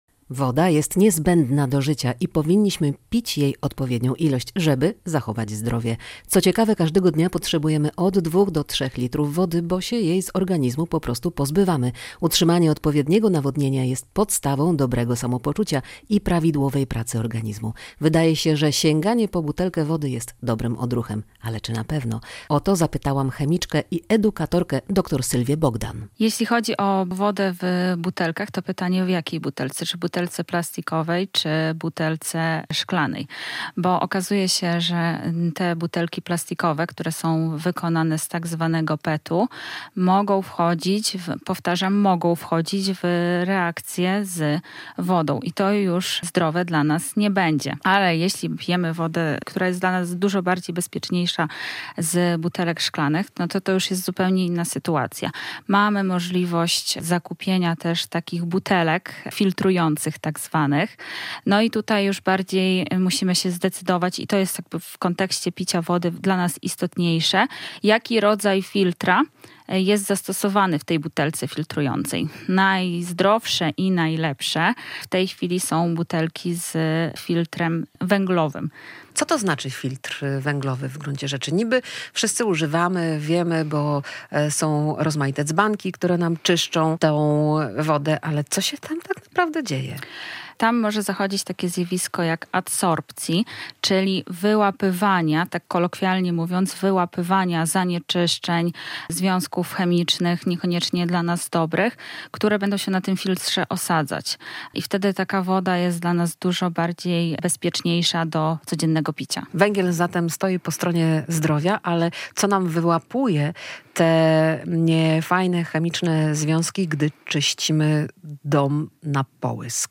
Prowadzący: